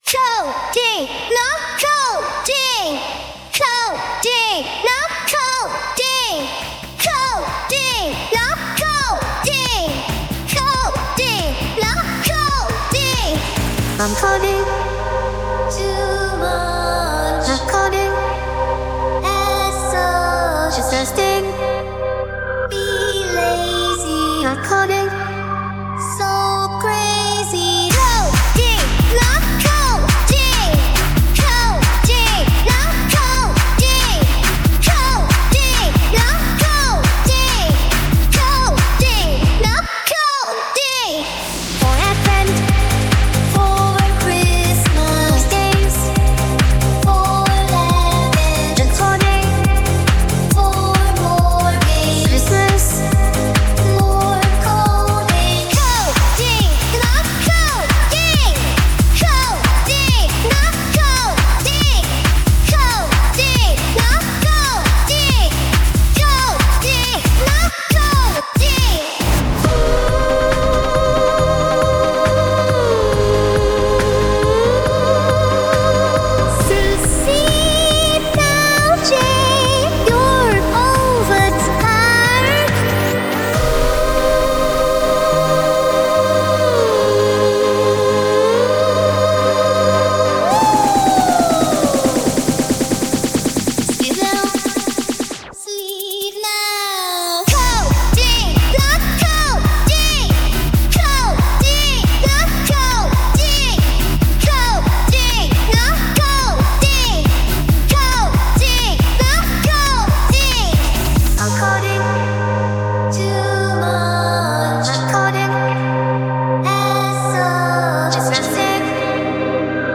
Sung by Udio